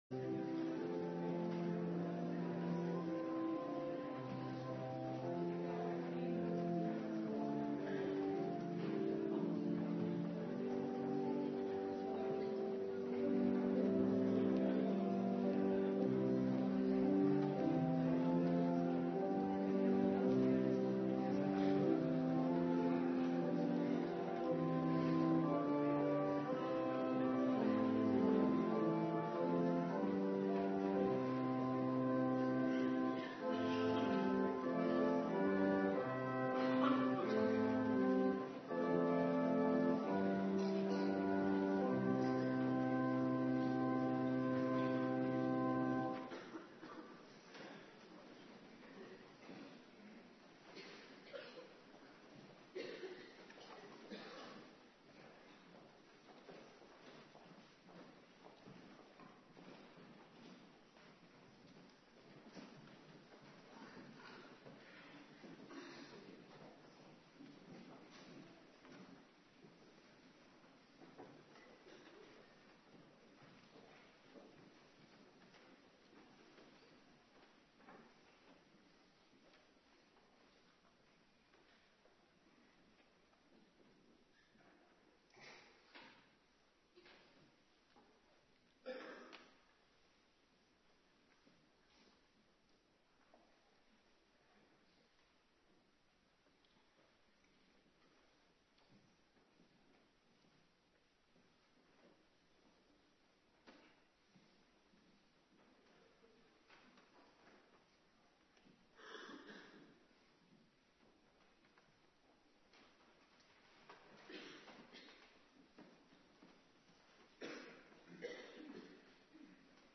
Morgendienst
09:30 t/m 11:00 Locatie: Hervormde Gemeente Waarder Agenda: Kerkdiensten Terugluisteren Doopdienst Zefanja 3:1-15